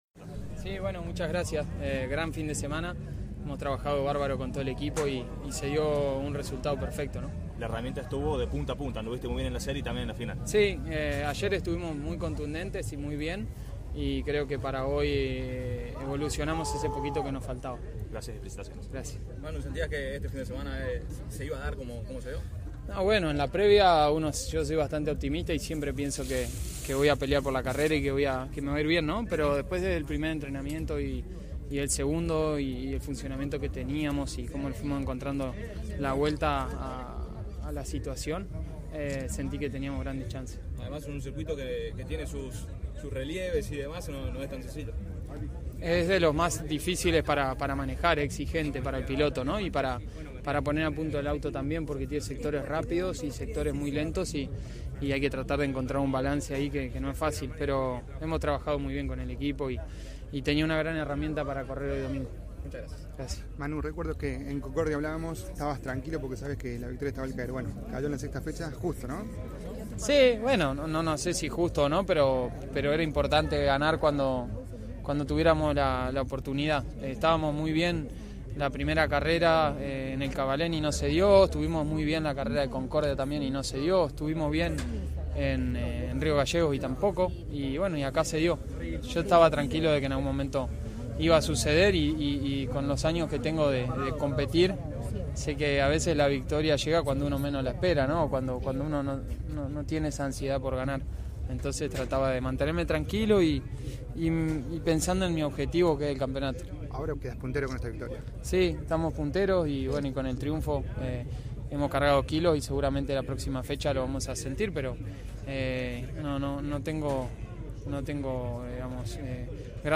Las actividades de la sexta fecha de la temporada del Turismo Nacional en Posdas, Misiones, se cerró con la final de la Clase 3 que, tras ella, CÓRDOBA COMPETICIÓN dialogó con los protagonistas.